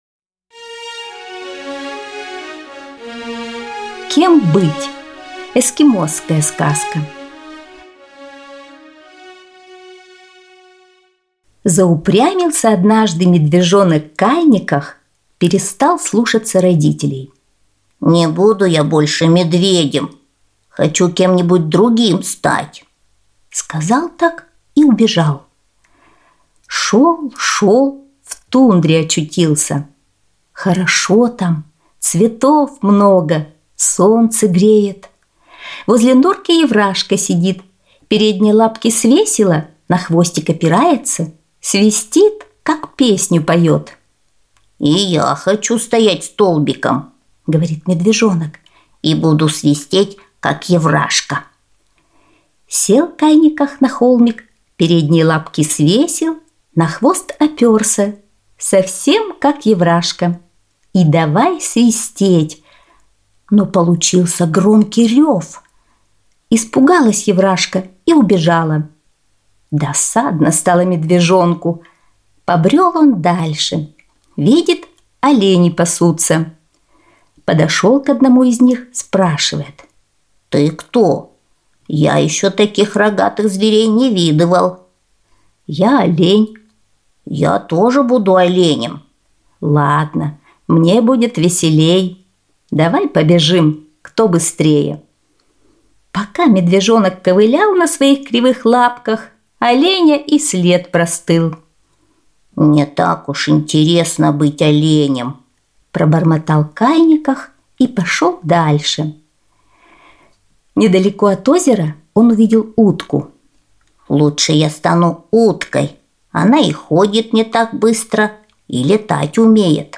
Эскимосская аудиосказка